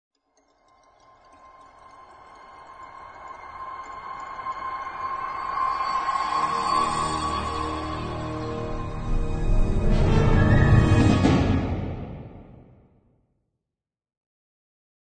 44.1 kHz 震撼大气片头音乐 全站素材均从网上搜集而来，仅限于学习交流。